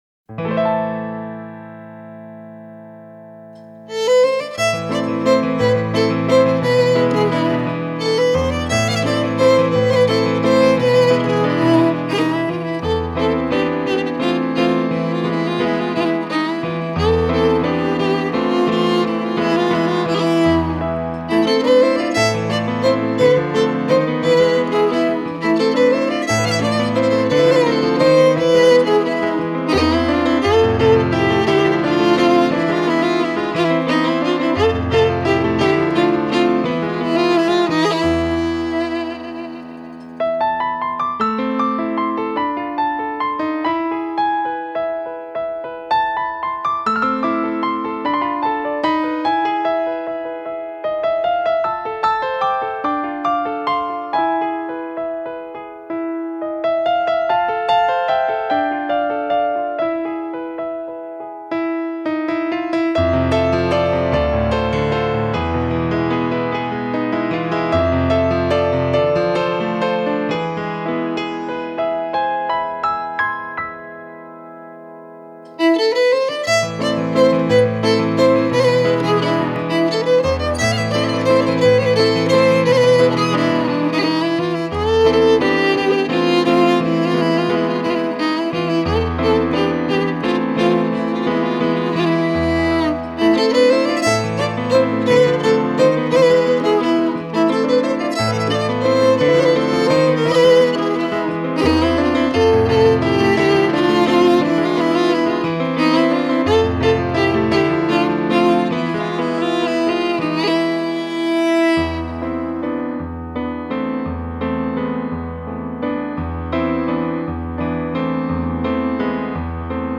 Violín
Piano